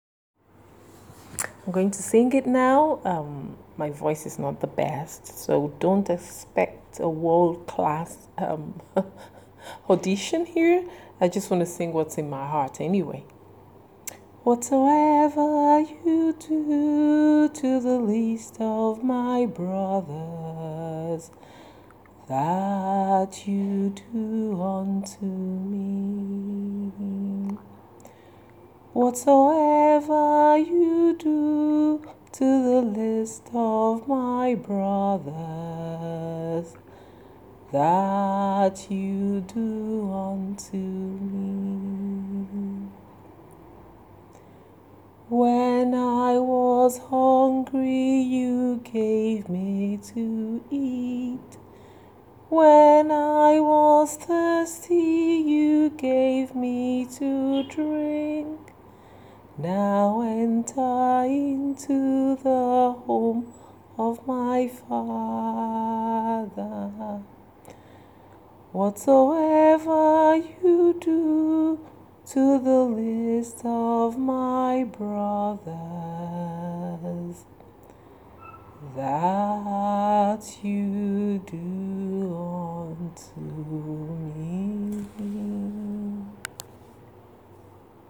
Here’s a little tune I played on my keyboard for you.